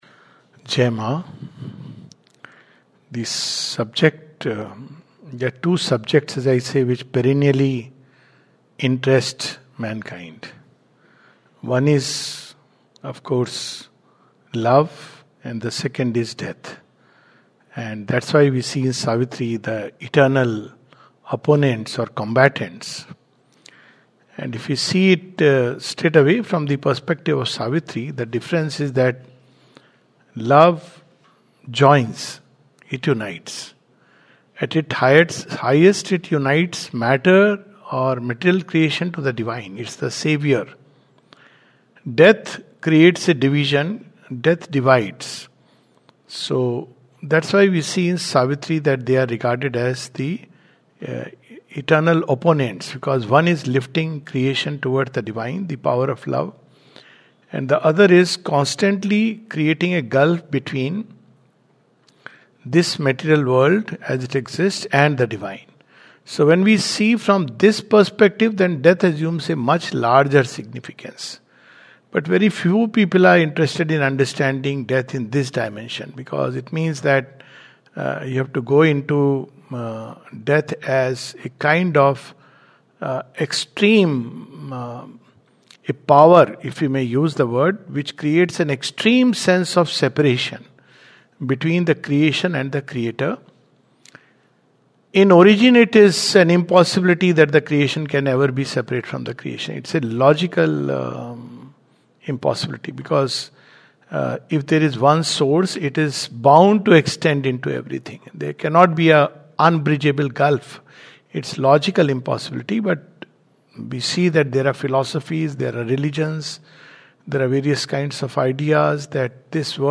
This talk